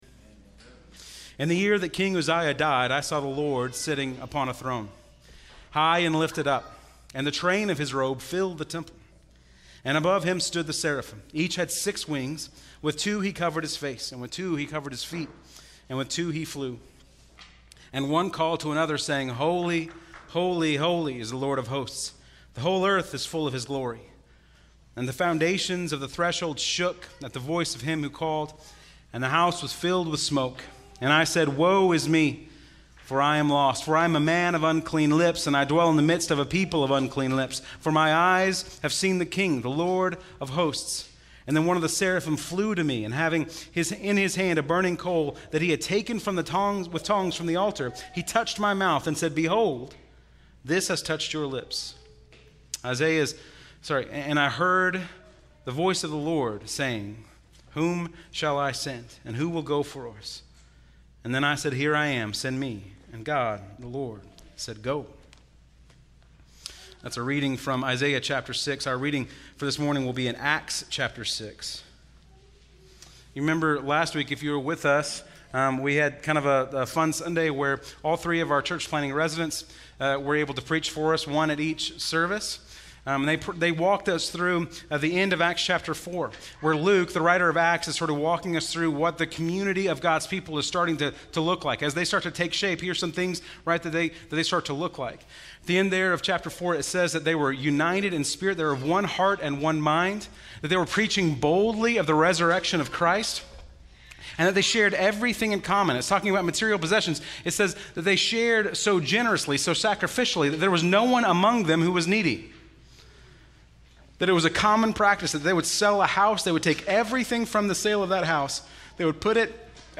Bible Text: Acts 5:1-11 | Preacher: